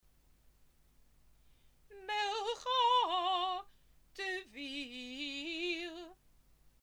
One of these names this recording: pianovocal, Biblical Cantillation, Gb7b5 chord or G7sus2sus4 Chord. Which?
Biblical Cantillation